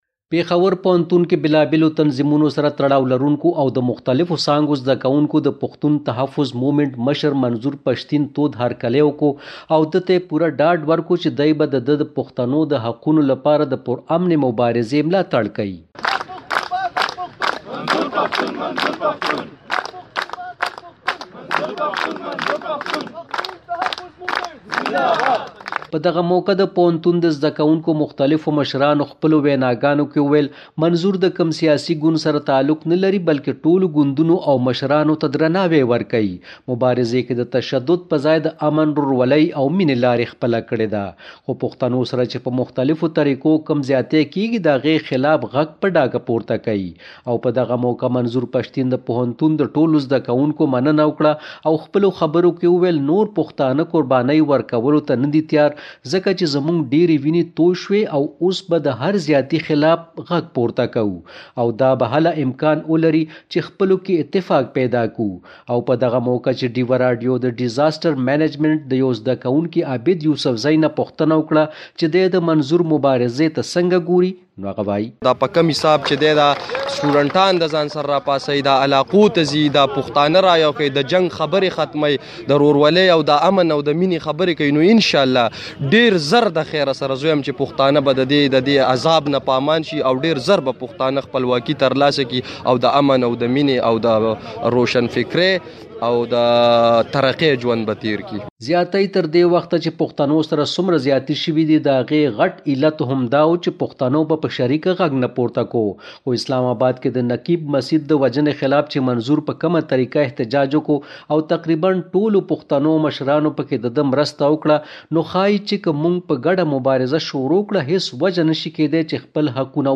منظور پشتین پیښور پوهنتون کې زده کوونکو ته وینا کوي
غږئیز رپورټ